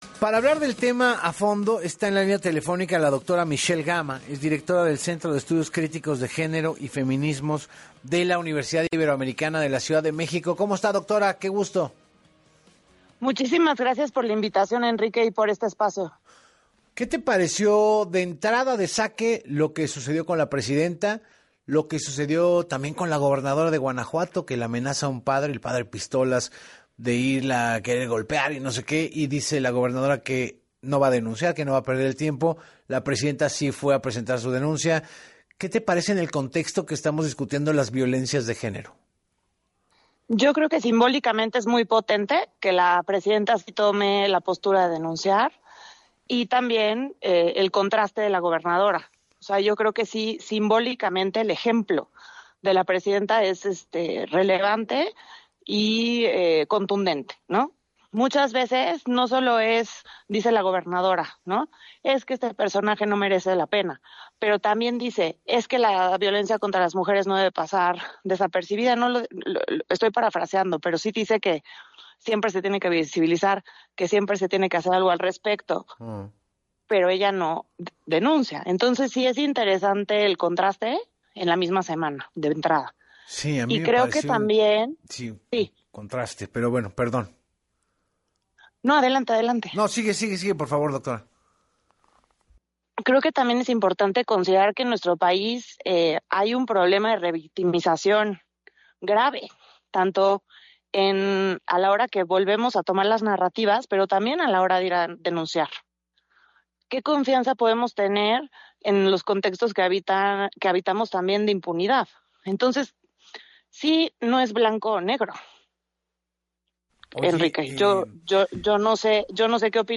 • Responsabilidad masculina: la experta coincidió con el conductor en que la lucha contra la violencia de género exige que los hombres dejen de considerar el cuerpo de la mujer como una “propiedad” y de vulnerarla a voluntad.